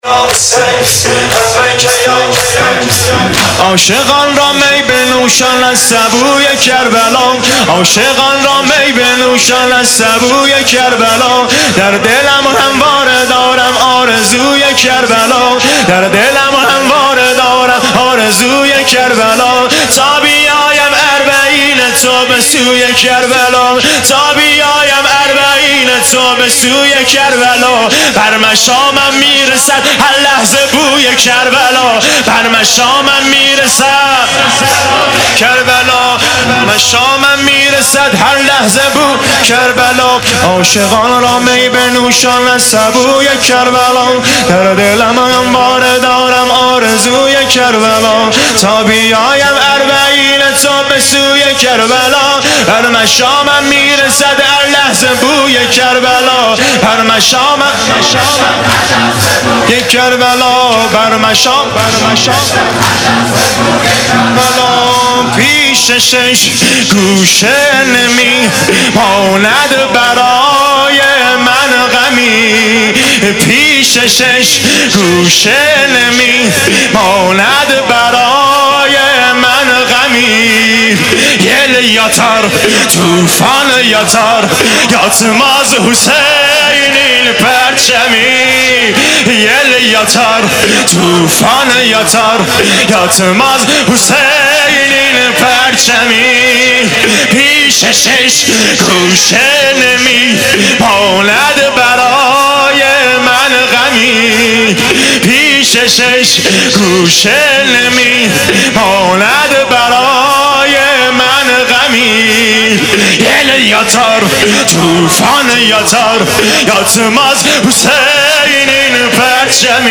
نوحه طوفانی با گوشواره یل یاتار طوفان یاتار_هیات جوانان حضرت علی اکبر کلور_استان اردبیل